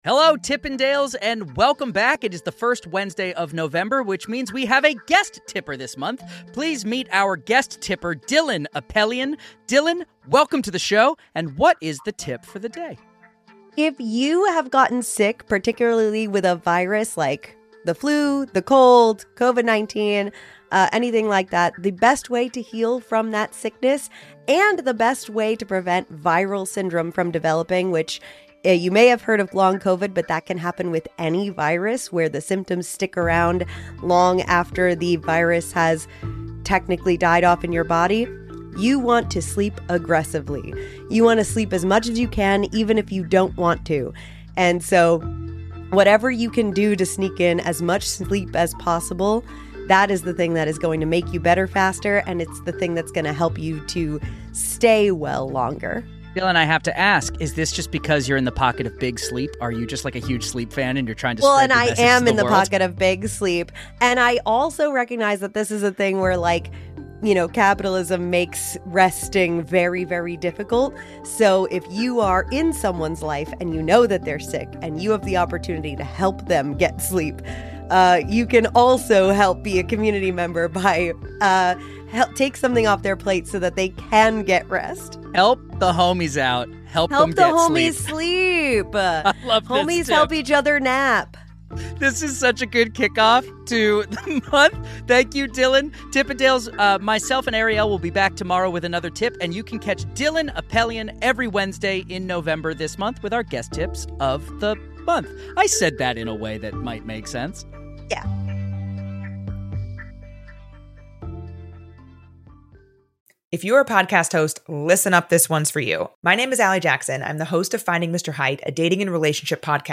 When you’re fighting a virus, sleep isn’t just recovery—it’s your primary weapon. Guest tipper